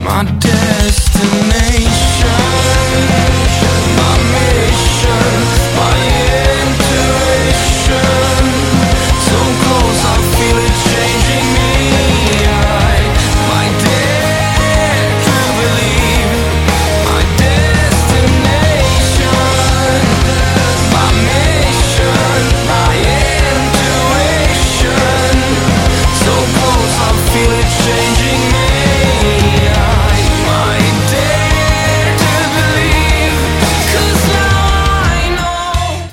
• Качество: 192, Stereo
alternative
Rock
Красивая, даже немного пафосная песня про забвение